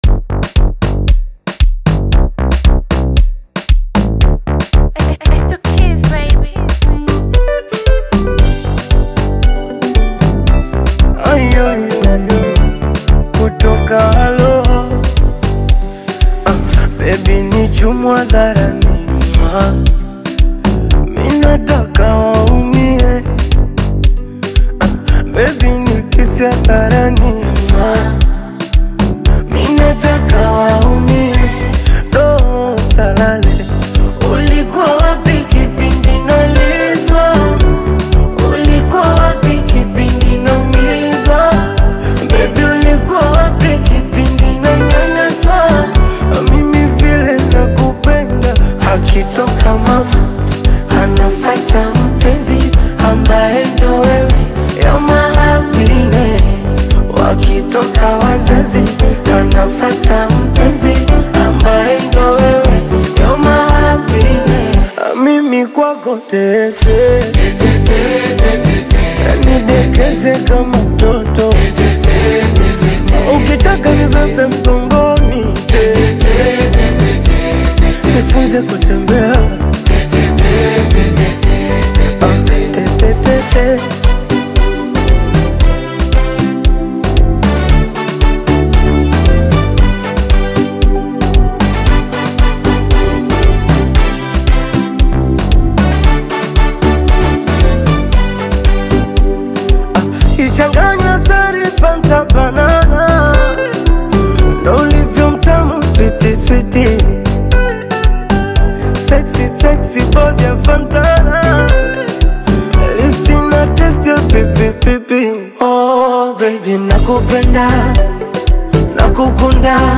soulful Afro-fusion love anthem
blending heartfelt Bongo Flava melodies with tender lyrics
Genre: Bongo Flava